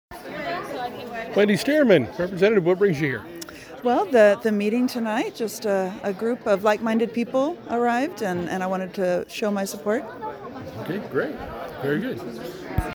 Rep. Stearman said,